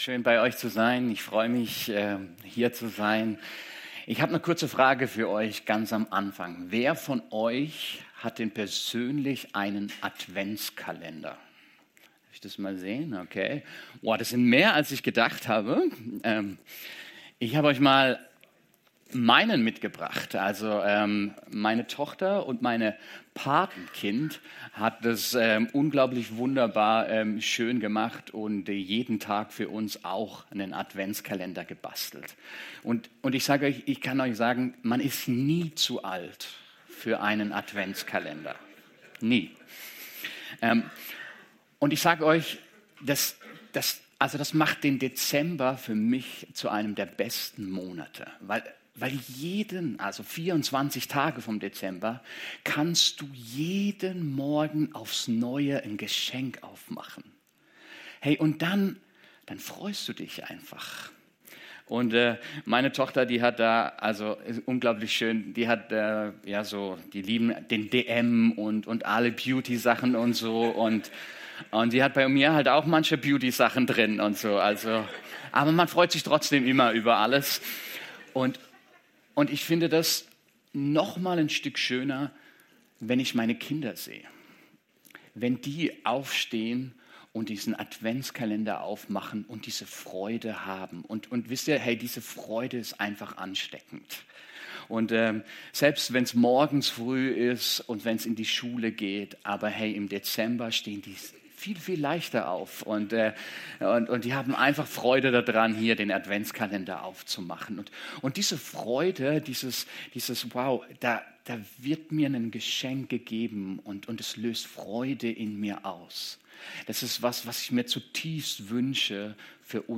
Predigt vom 21.12.2025 | Podcast der Stadtmission Alzey